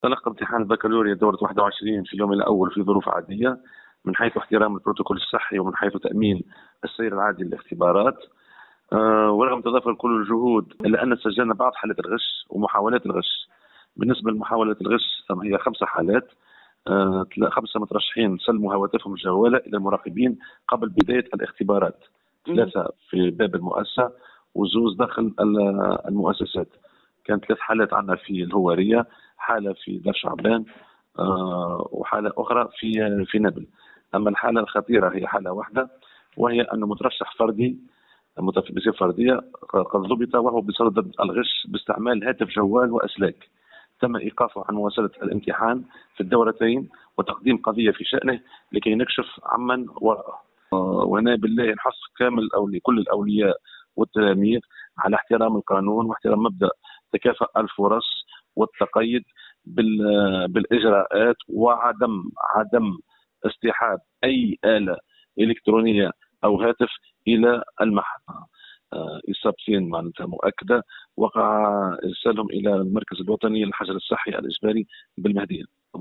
وأضاف في تصريح لمراسلة "الجوهرة أف أم" أنه تم تسجيل 3 حالات بالهوارية وحالة بدار شعبان الفهري وحالة بنابل.